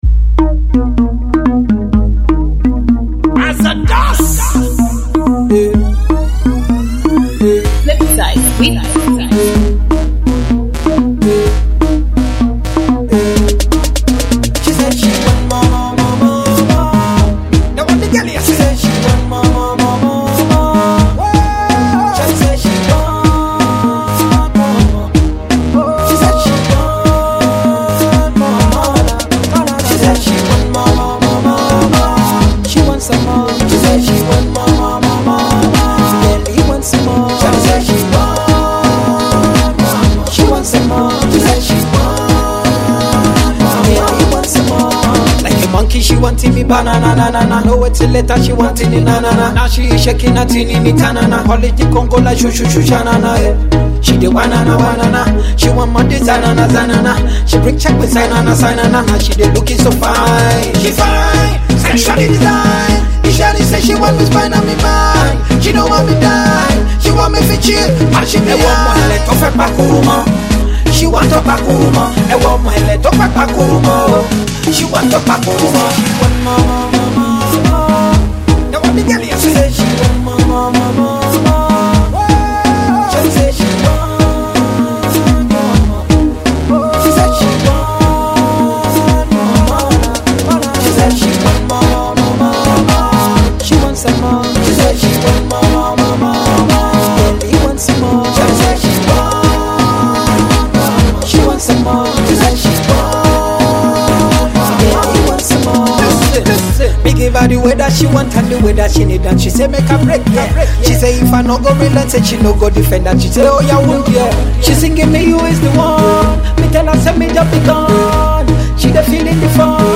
Pop
Nice party jam produced by star producer